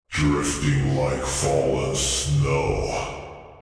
Subject description: Some very magnetic low pitched voices for my self made unit 'Omicron'!
I used professional dubbing tools and workflows, and cooperated with a stunning post-processing assistant.
Very Apocalypse-like these.